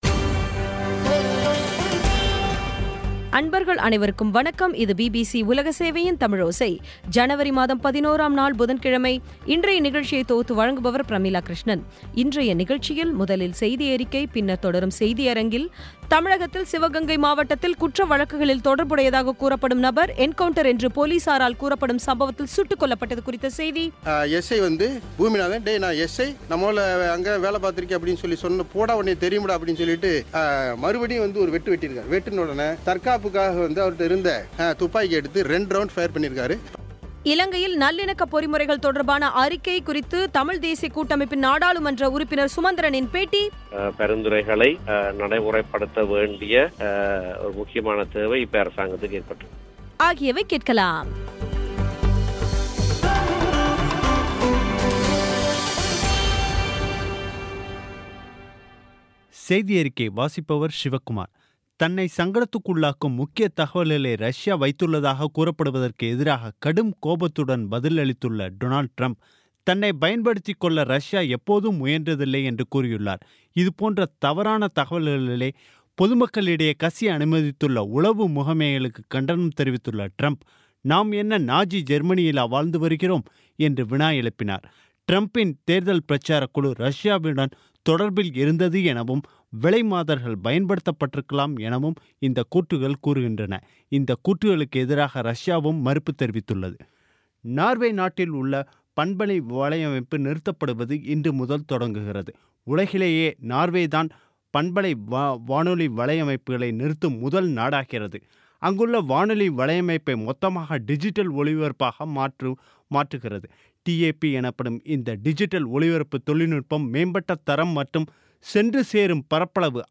இன்றைய நிகழ்ச்சியில் முதலில் செய்தியறிக்கை, பின்னர் தொடரும் செய்தியரங்கில்தமிழகத்தில் சிவகங்கை மாவட்டத்தில் குற்ற வழக்குகளில் தொடர்புடையதாகக் கூறப்படும் நபர் என்கவுண்டர் என்று போலிசார் கூறும் சம்பவத்தில் சுட்டுக்கொல்லப்பட்டது குறித்த செய்தி